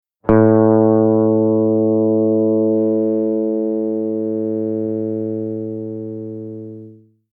Simply click the play button to get the sound of the note for each string (E, A, D, G, B and E).
A String
a-note.mp3